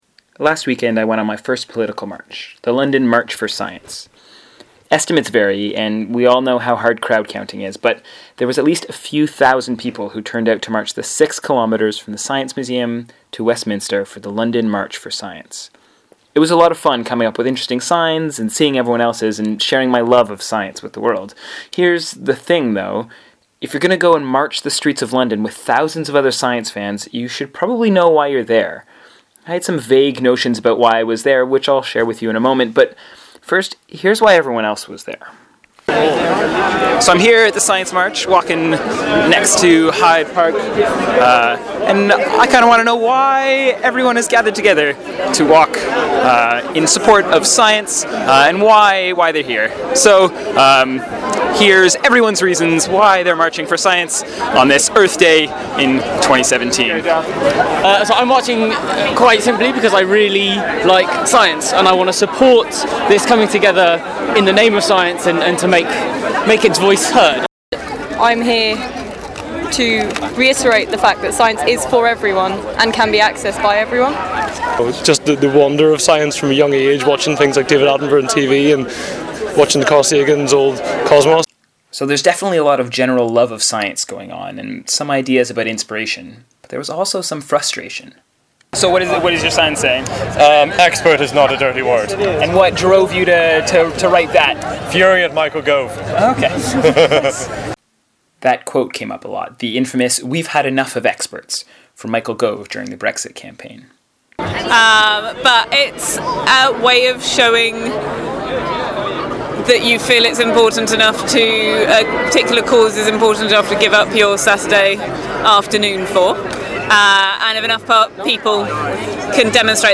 I wanted to know why everyone else was there, though, so I brought along a voice recorder and asked around.
marching-for-science.mp3